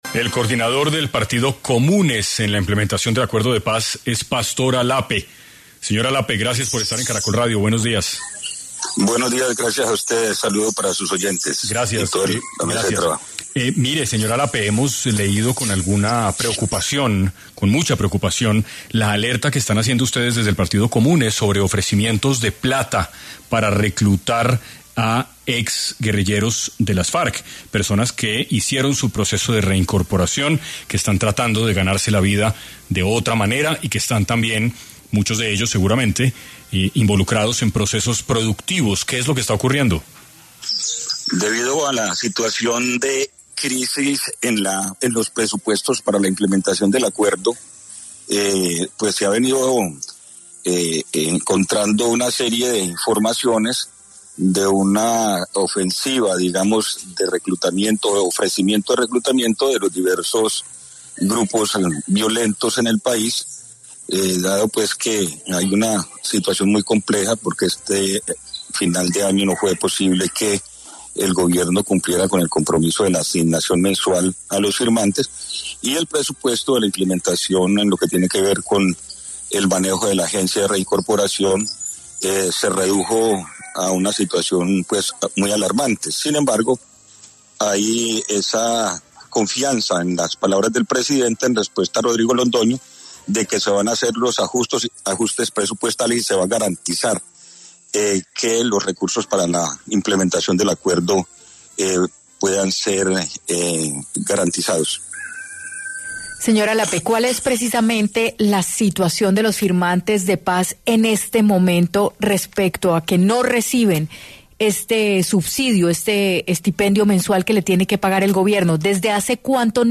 En 6AM estuvo Pastor Alape, coordinador del Partido Comunes de la implementación del acuerdo de paz, quien alertó sobre ofrecimientos de dinero para reclutar exmiembros de las FARC.